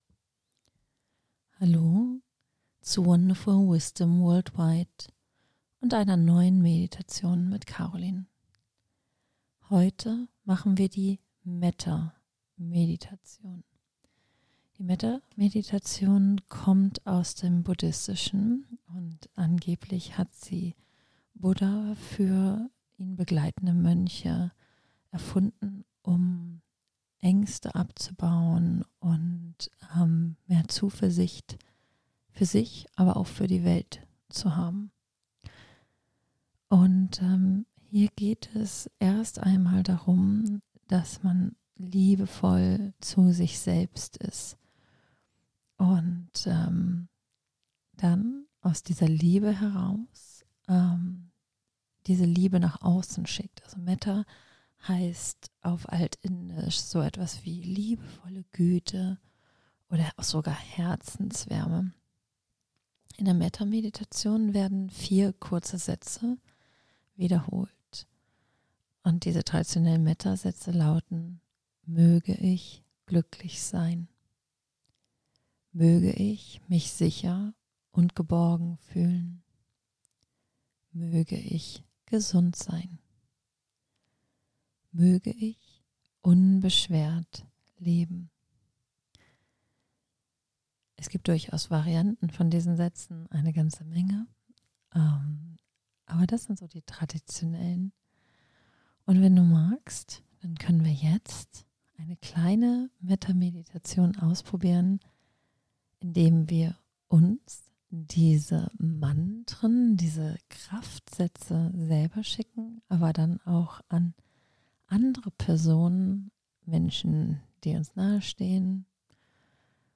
Darum teile ich mit dir: 💓 Eine Metta-Meditation für Selbstmitgefühl (ca. 12 Minuten) Die Metta-Praxis – auch bekannt als Loving-Kindness Meditation – ist eine traditionelle buddhistische Meditation, die dich unterstützt, mit dir selbst in einen wohlwollenden Kontakt zu kommen. In dieser geführten Meditation (mit kurzer gesprochener Einführung zu Beginn) richtest du liebevolle Wünsche zunächst an dich selbst – und dann auch an andere.
Metta-Mediation.mp3